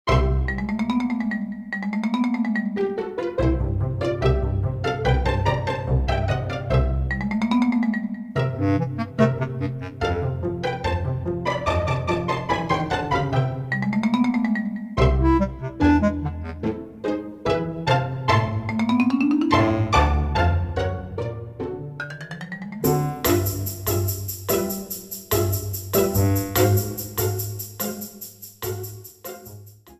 The music for the file select screen